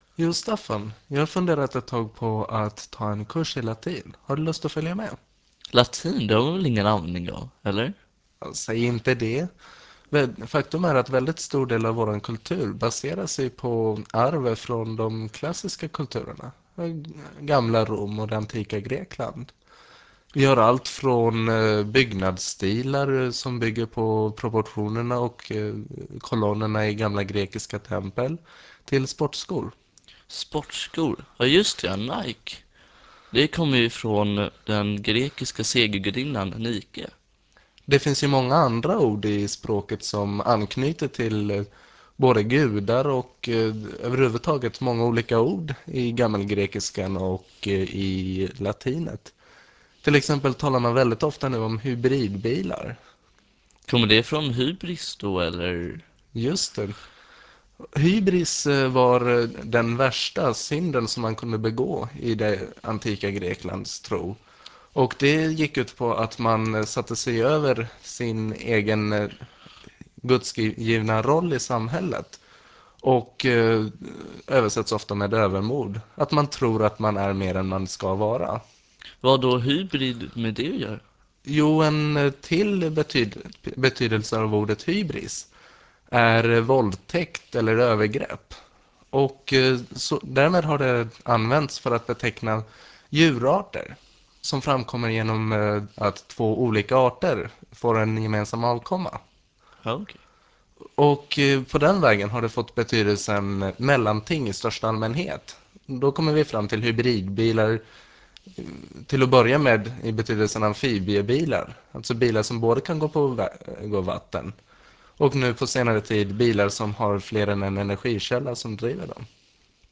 Föredraget handlar om filosofi och sänds mars 1999 i Etervåg.